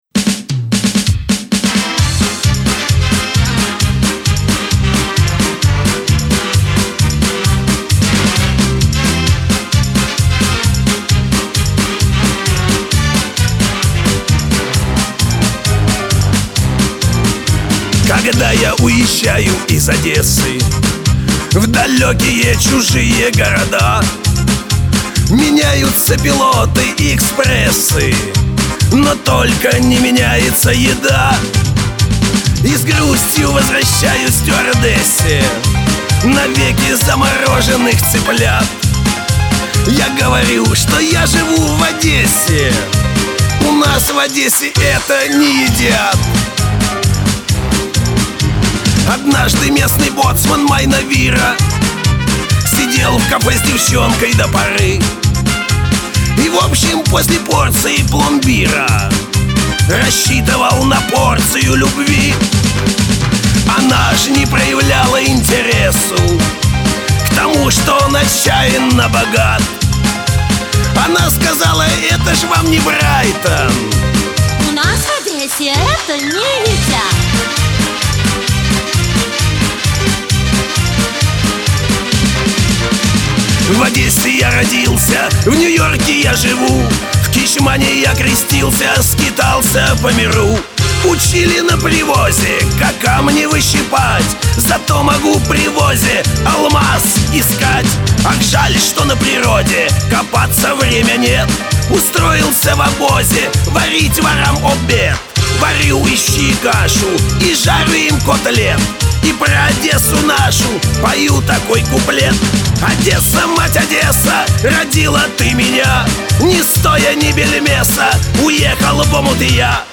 Оба с азартом, оба искренние, оба слушаются достойно!